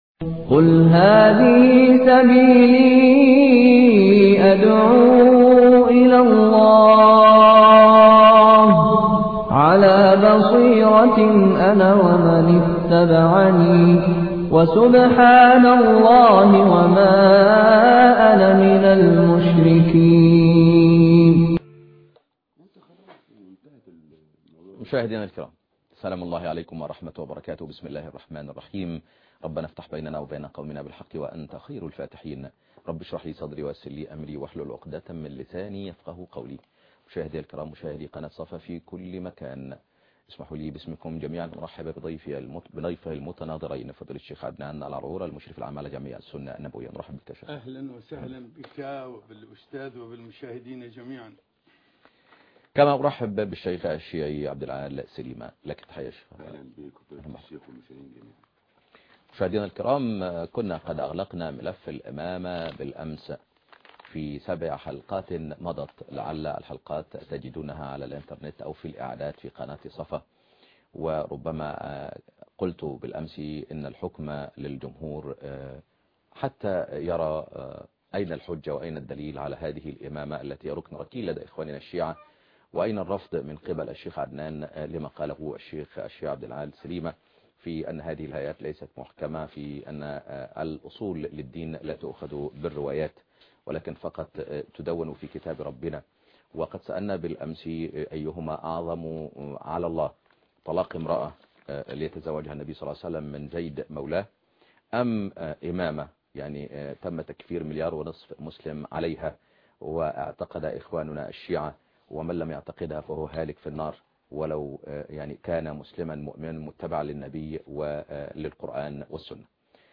مناظرة